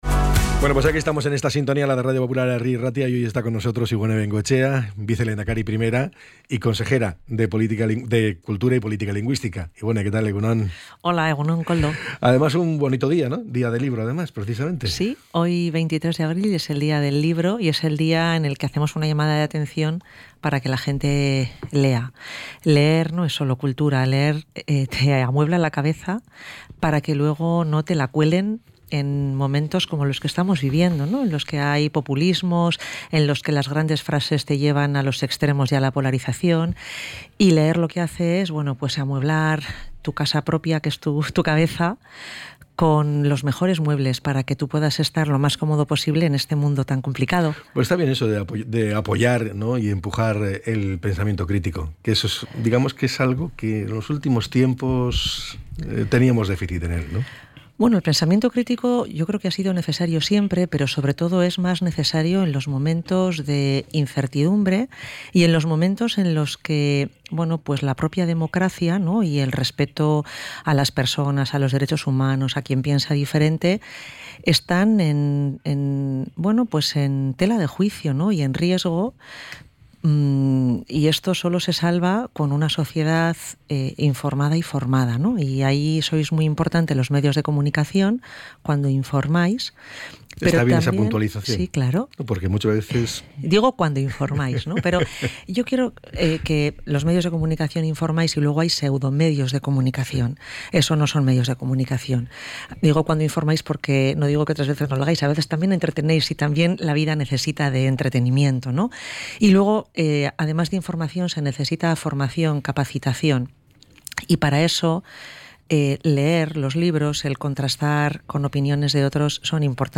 ENTREV.-IBONE-BENGOETXEA.mp3